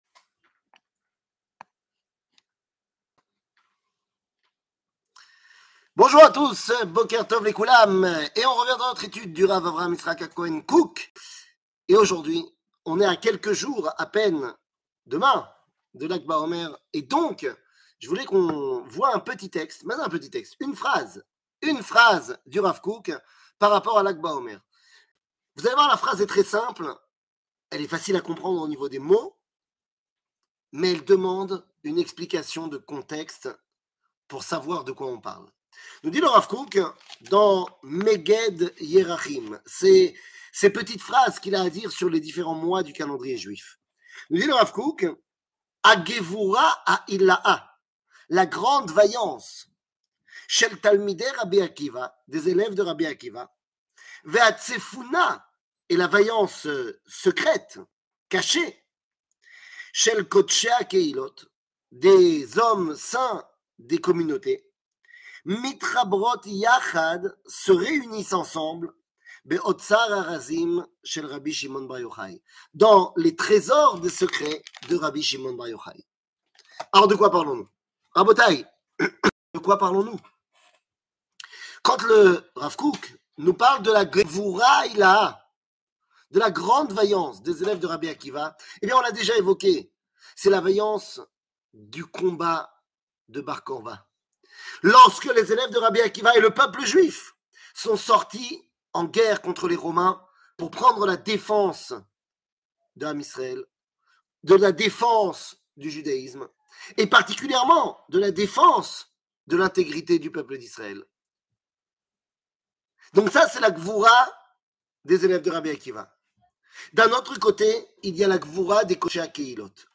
Rav Kook, Lag Baomer (Meged Yerahim, Iyar) 00:04:41 Rav Kook, Lag Baomer (Meged Yerahim, Iyar) שיעור מ 18 מאי 2022 04MIN הורדה בקובץ אודיו MP3 (4.28 Mo) הורדה בקובץ וידאו MP4 (12.5 Mo) TAGS : שיעורים קצרים